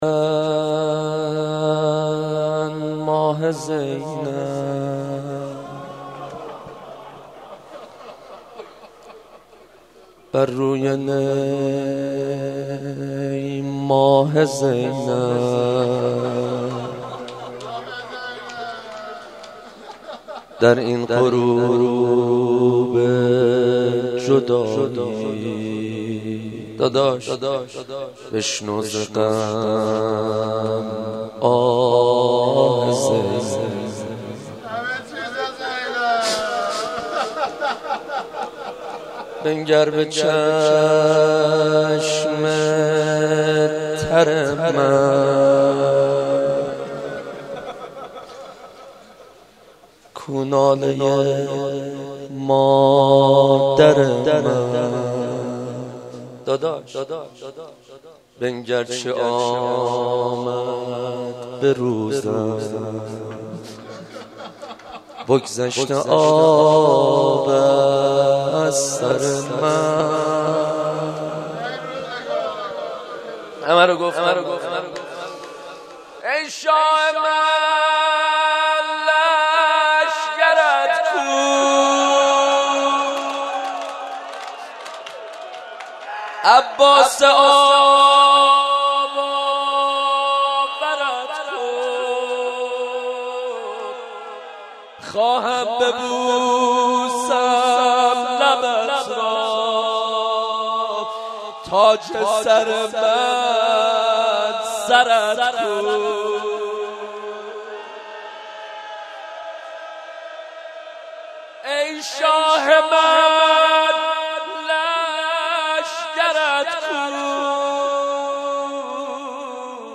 شب چهاردهم رمضان93